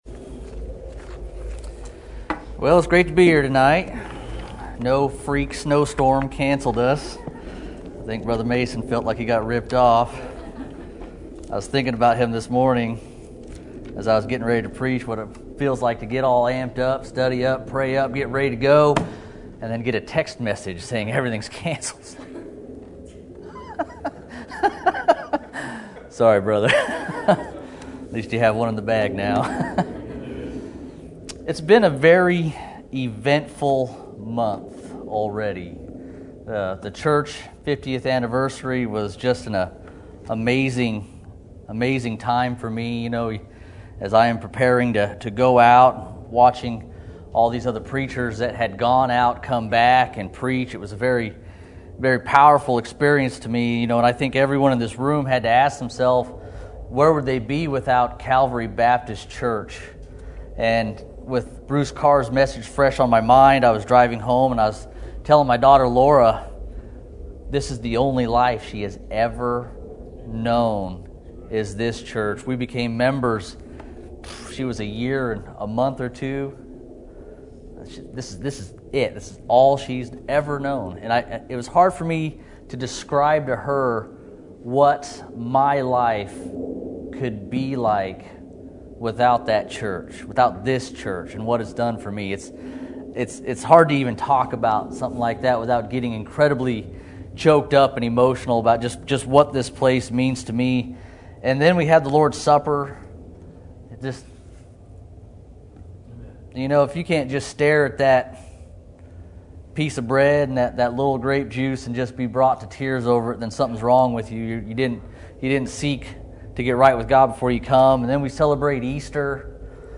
Sermon Topic: General Sermon Type: Service Sermon Audio: Sermon download: Download (24.87 MB) Sermon Tags: Ephesians Doctrine Authority God's Word